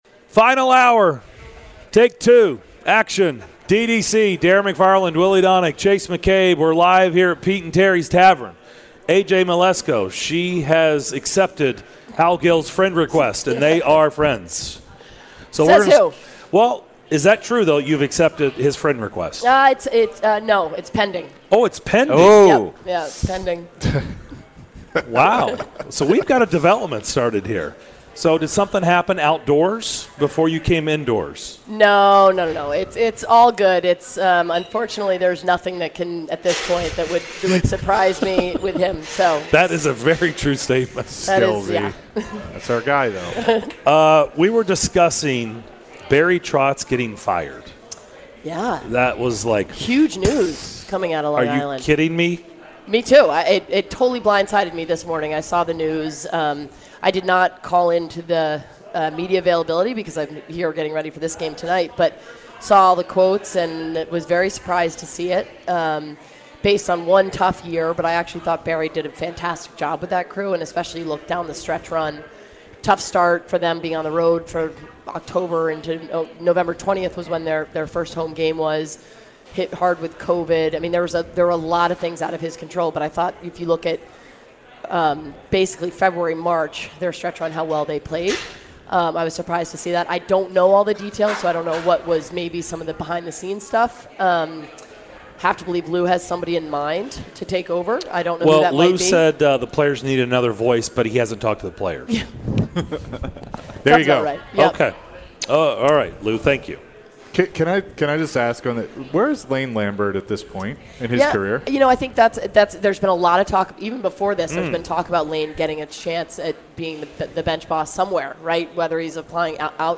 ESPN hockey analyst AJ Mleczko talks Barry Trotz firing in NYI and Preds/Avs Game 4